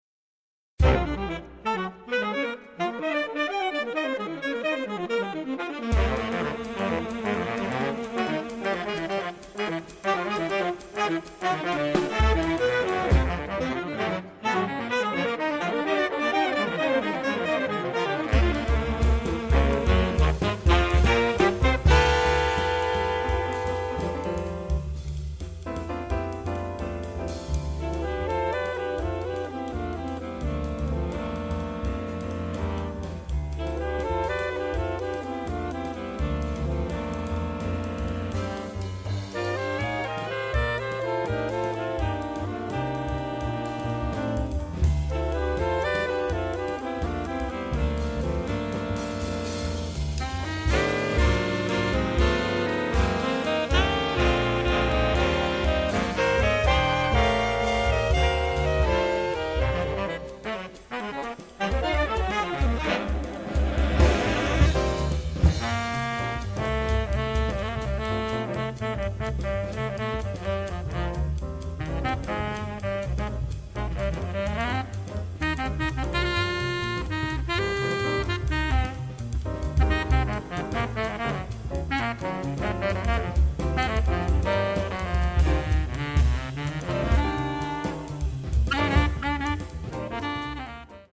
5 Saxophones AATTBar & Piano, Guitar (Ad Lib), Double Bass
Voicing: SaxQuintet